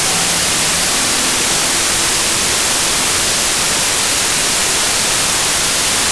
the melody in noise.
melonois.wav